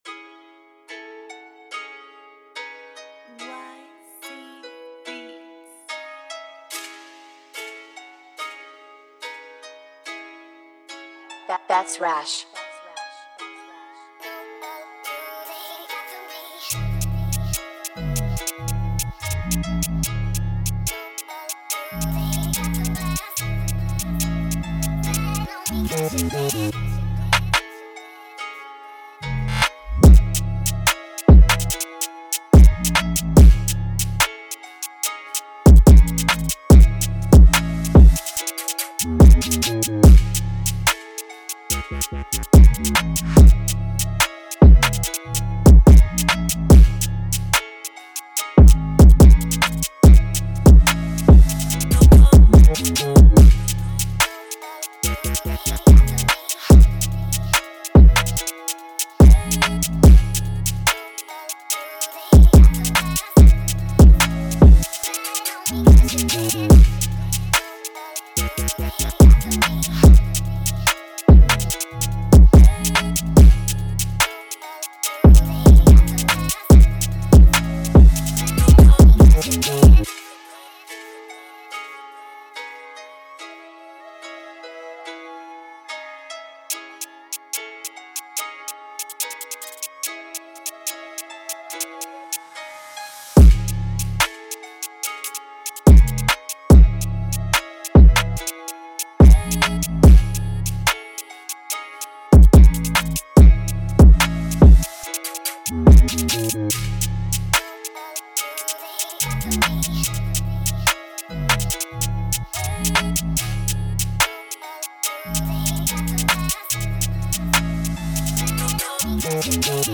• 20 Melody Loops